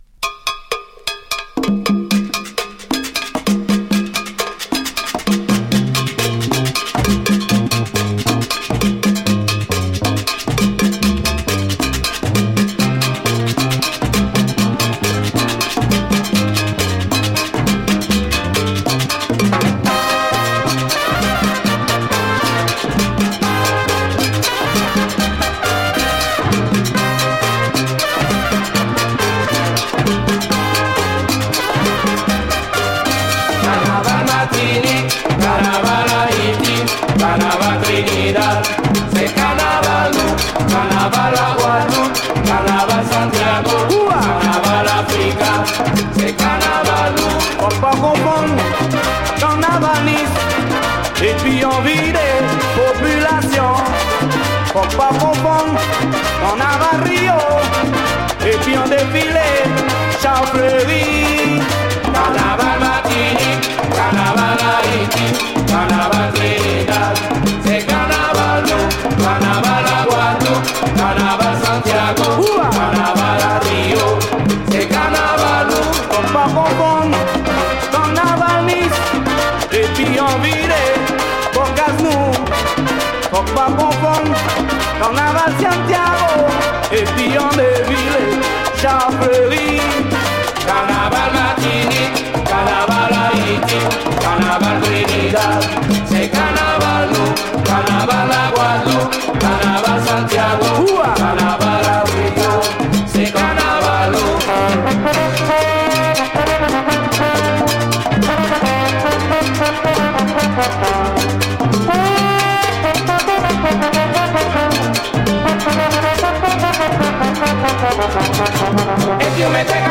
ダイナミック且つ演奏クォリティーも非常に高いラテン、カリビアン・チューンが満載。